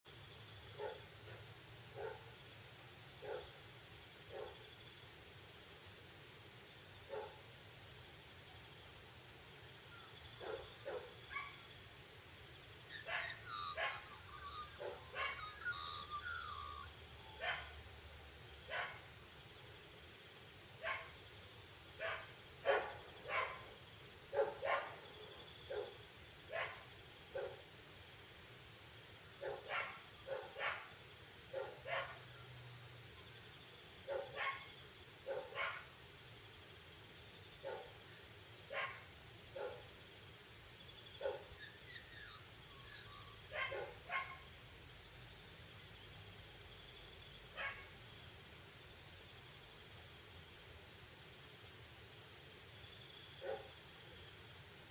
Sound Effects
Dogs Bark Back